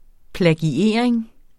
Udtale [ plagiˈeˀɐ̯eŋ ]